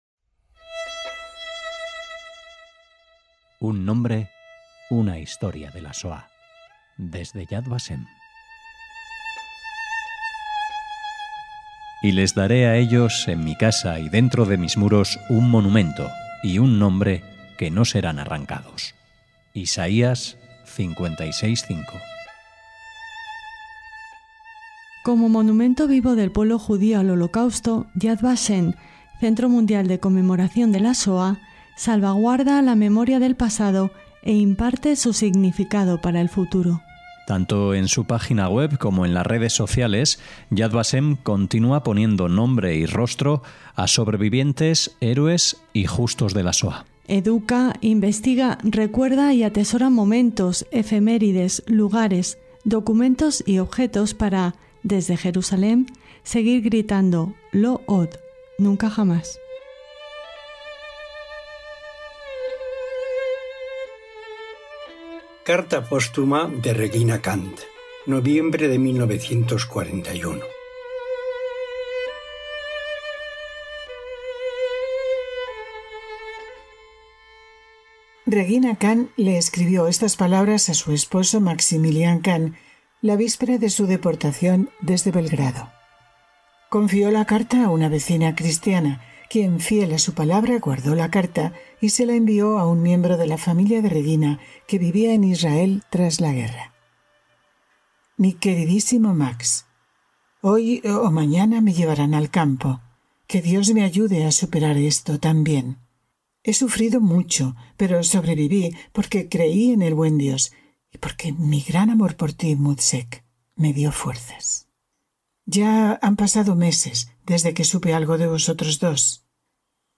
Locución y edición del texto